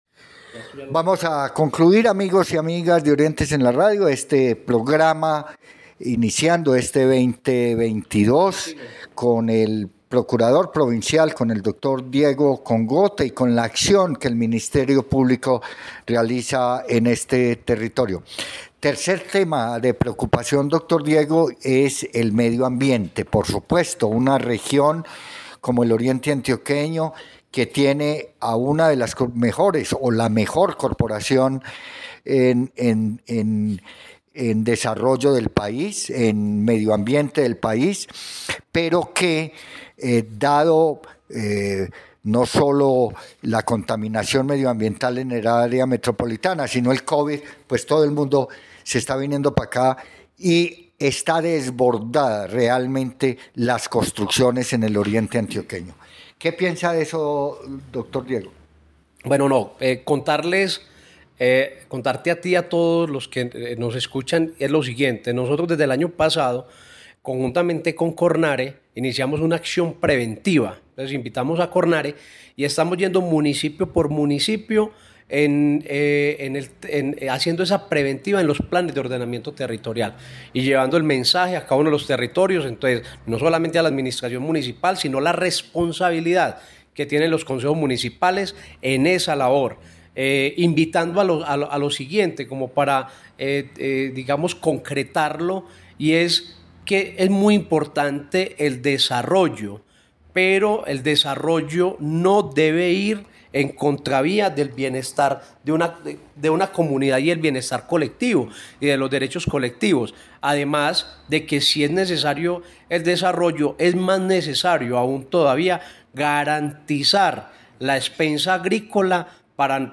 Entrevista con el procurador provincial:
Entrevista-con-el-procurador-parte-3.mp3